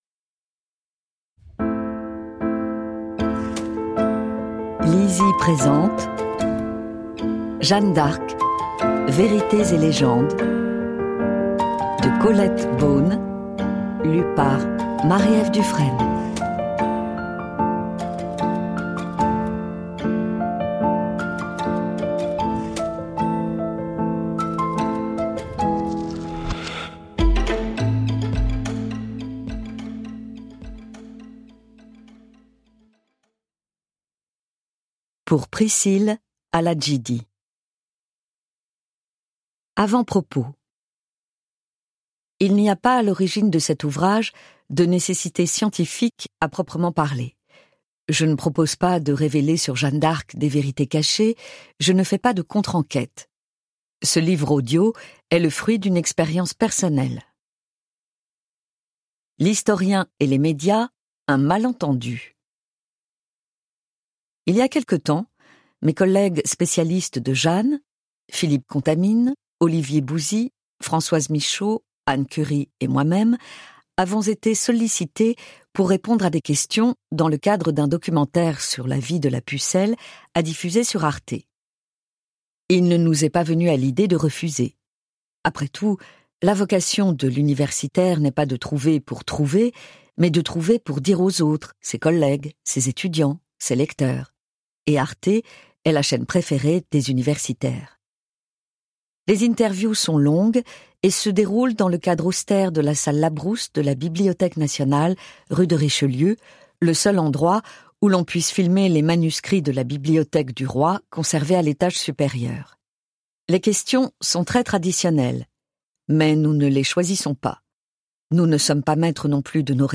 je découvre un extrait - Jeanne d'Arc, vérités et légendes de Colette BEAUNE
Ce livre audio se propose, en quelques chapitres très clairs, de couper court aux pires bêtises qui circulent encore sur Jeanne.